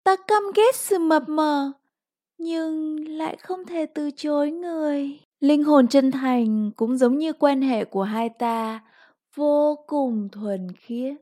• 5Vietnamese Female No.5
Game Characters